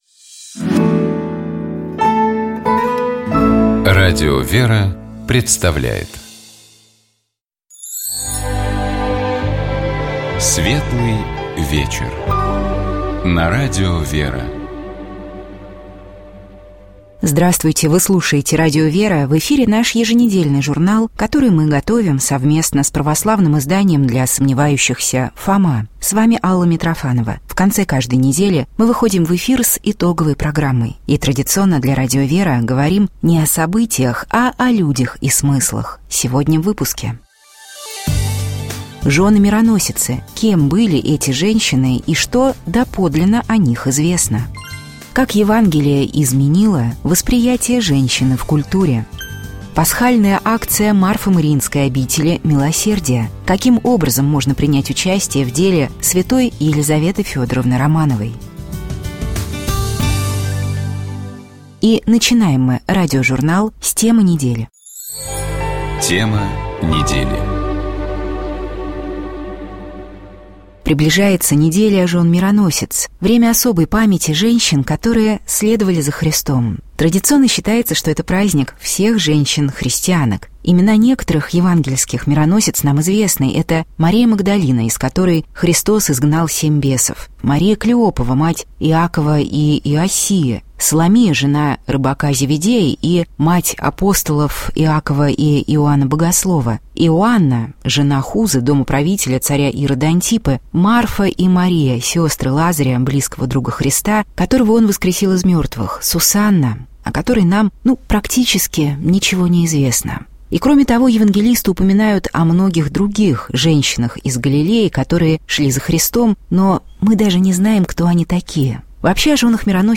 Общая теплая палитра программы «Еженедельный журнал» складывается из различных рубрик: эксперты комментируют яркие события, священники объясняют евангельские фрагменты, специалисты дают полезные советы, представители фондов рассказывают о своих подопечных, которым требуется поддержка.